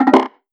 password-fail.wav